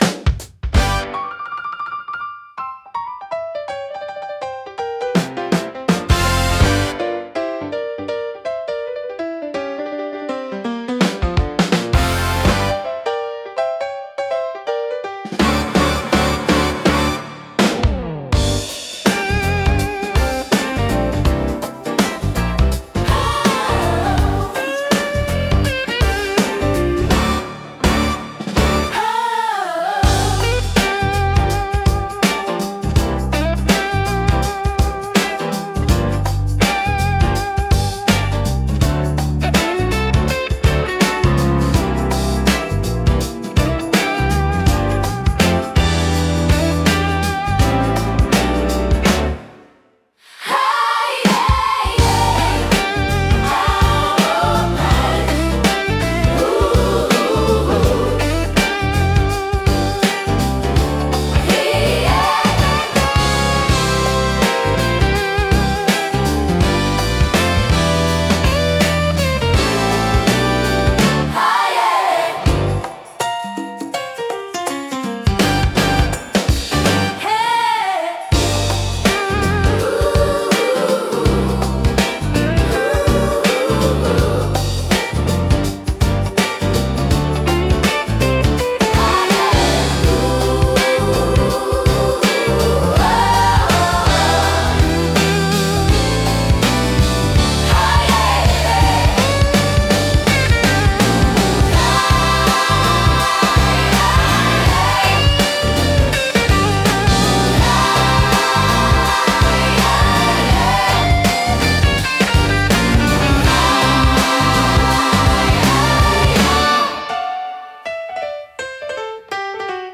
しっとりとした雰囲気を求める場面で活用されるジャンルです。